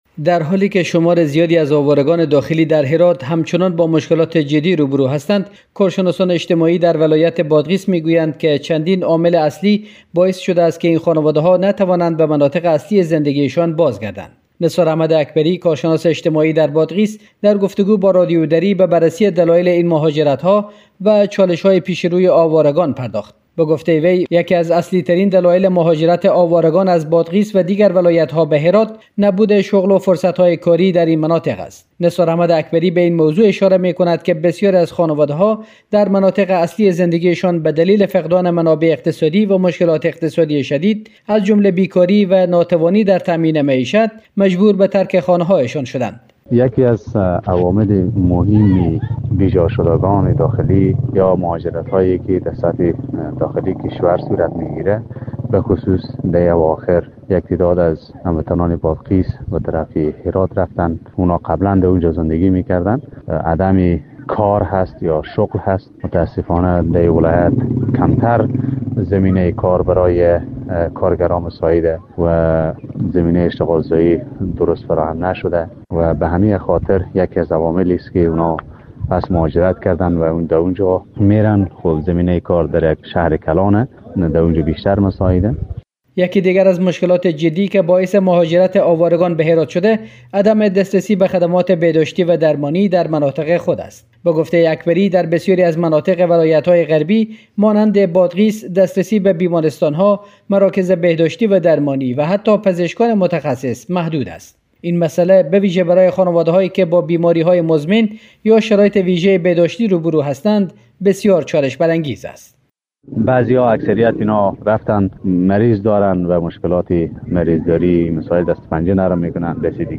در گفت وگو با رادیو دری